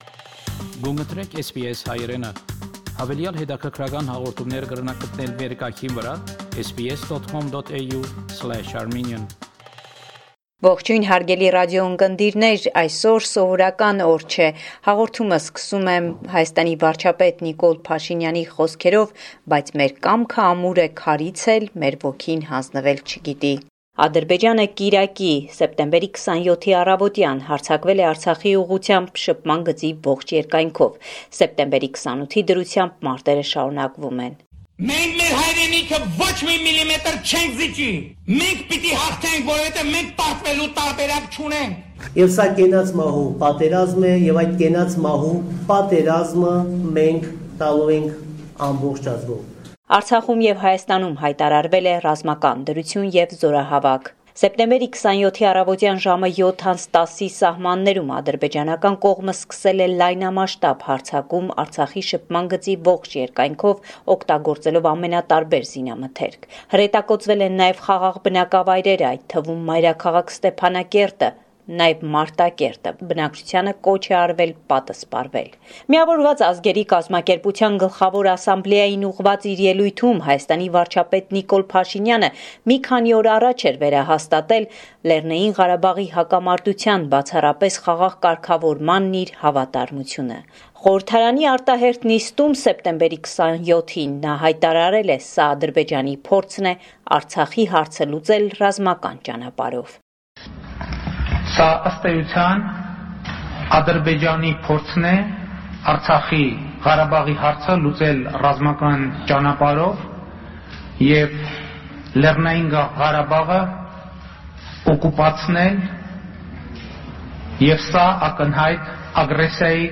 News from Armenia, Artsakh and the Diaspora